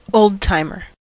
old-timer: Wikimedia Commons US English Pronunciations
En-us-old-timer.WAV